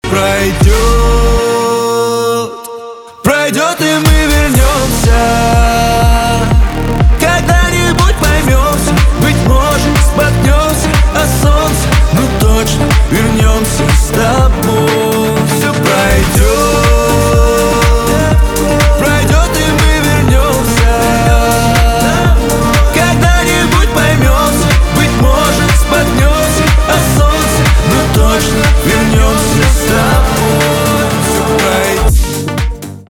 поп
битовые , басы , качающие , кайфовые
чувственные